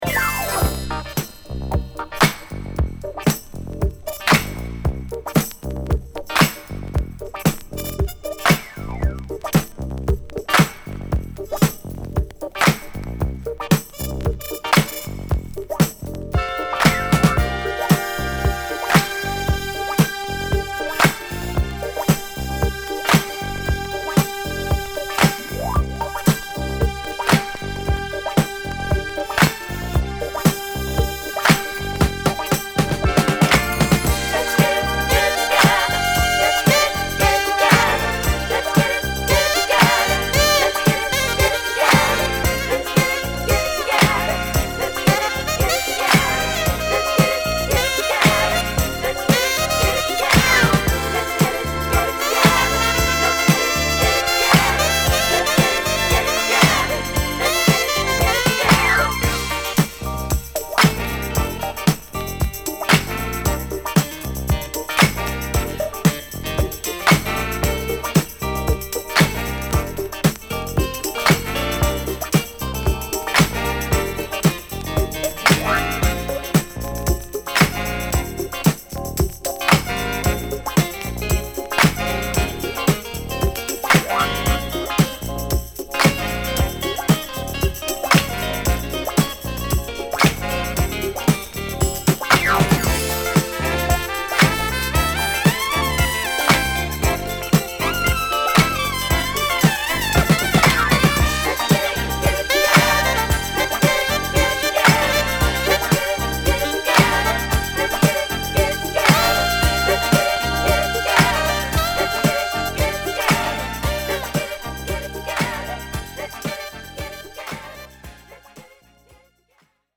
UK Only Remix!!
Disco~Garage Classic!!